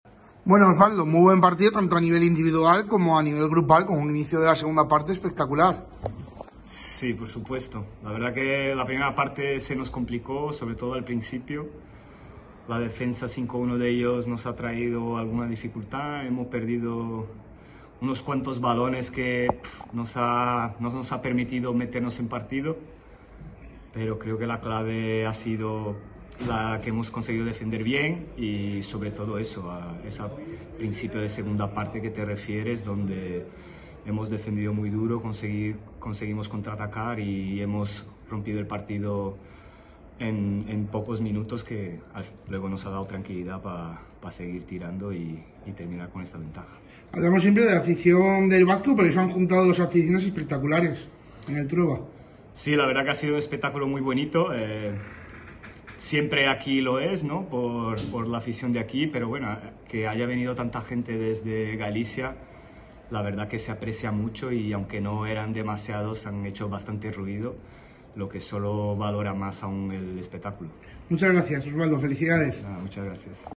Declaraciones
postpartido